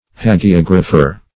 Hagiographer \Ha`gi*og"ra*pher\ (-f[~e]r), n.